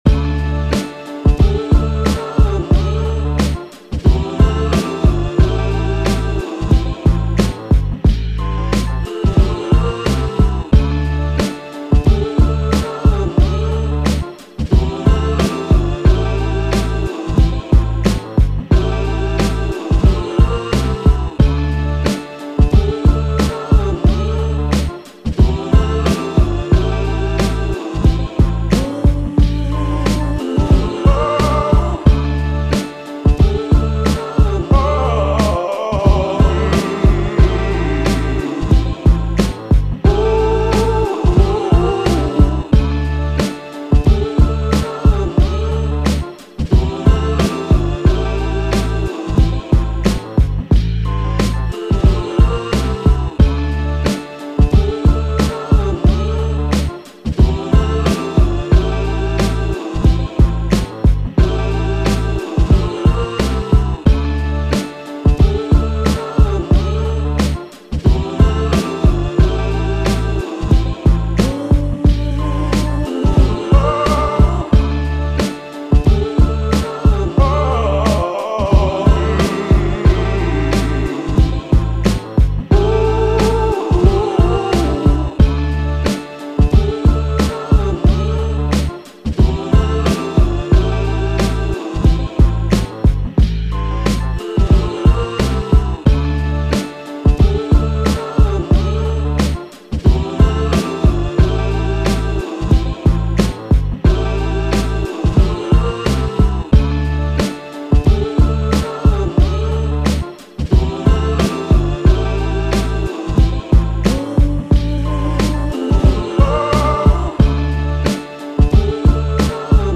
Hip Hop, 90s
Am